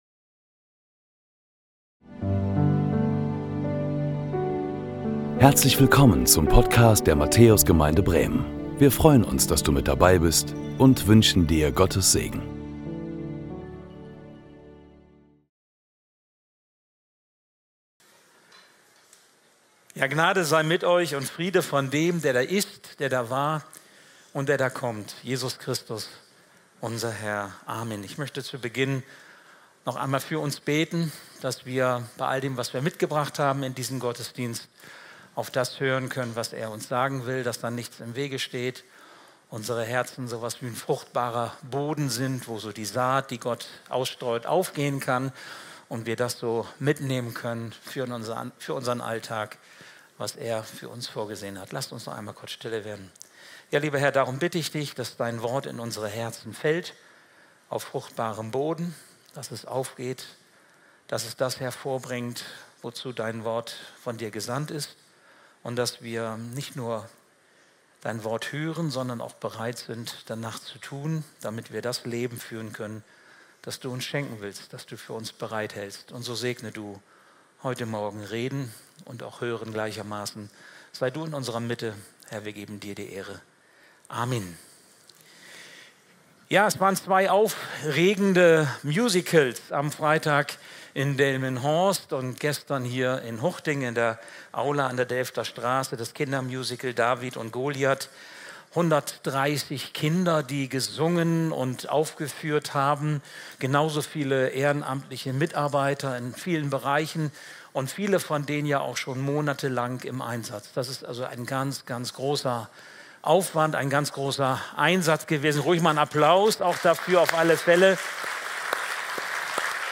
Predigten der Matthäus Gemeinde Bremen Dein Schatz Play Episode Pause Episode Mute/Unmute Episode Rewind 10 Seconds 1x Fast Forward 30 seconds 00:00 / 41:29 Abonnieren Teilen Apple Podcasts RSS Spotify RSS Feed Teilen Link Embed